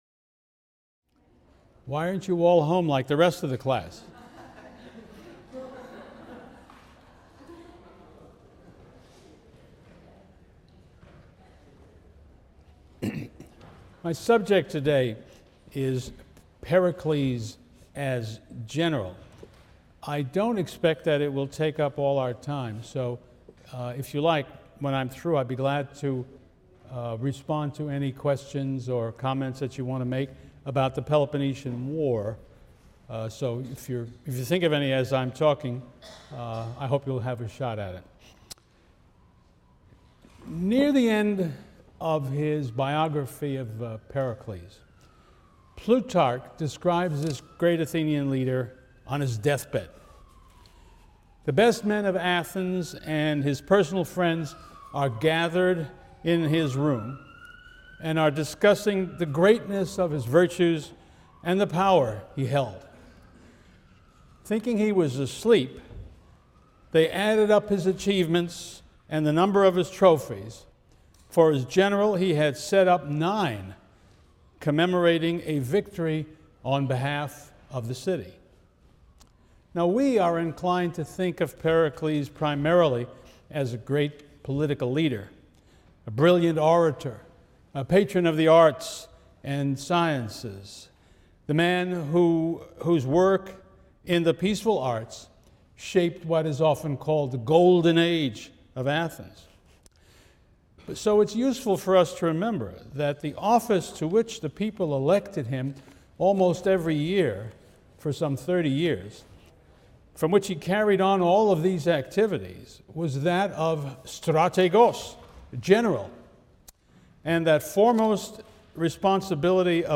CLCV 205 - Lecture 20 - The Peloponnesian War, Part II (cont.) | Open Yale Courses